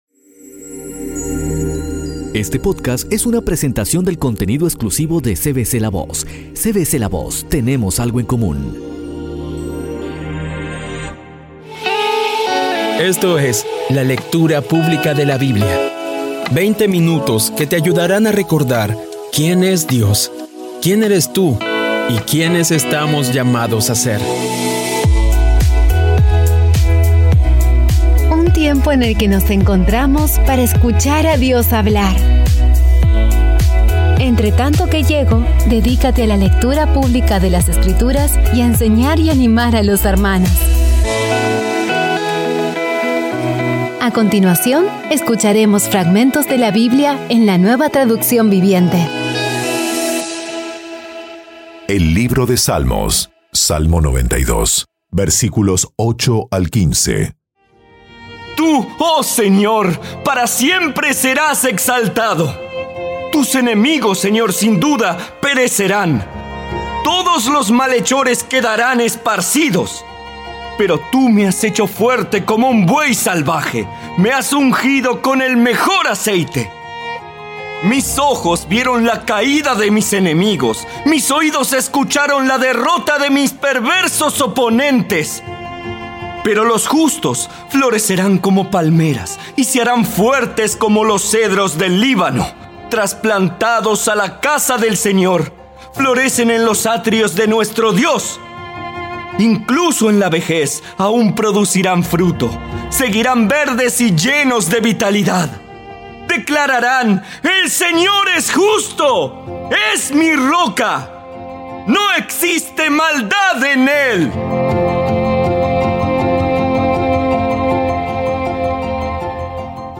Audio Biblia Dramatizada por CVCLAVOZ / Audio Biblia Dramatizada Episodio 226
Poco a poco y con las maravillosas voces actuadas de los protagonistas vas degustando las palabras de esa guía que Dios nos dio.